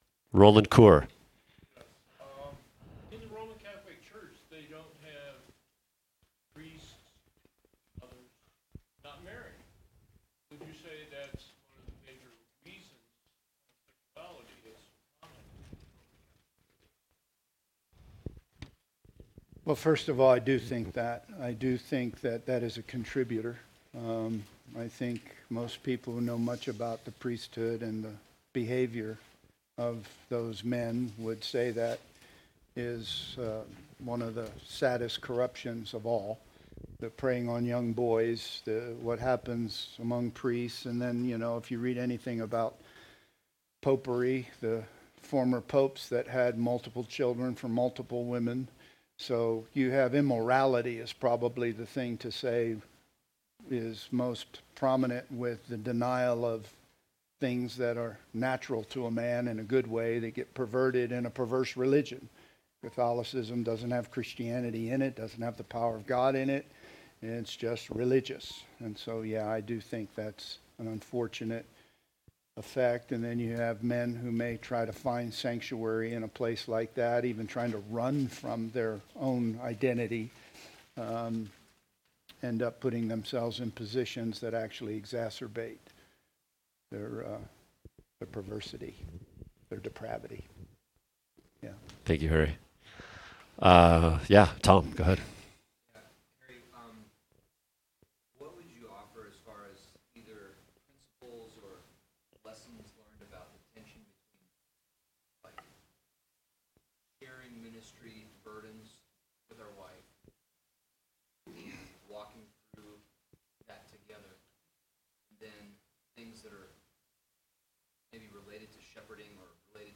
[TMF Conference 2023] Session 4 – Q&A Session | Cornerstone Church - Jackson Hole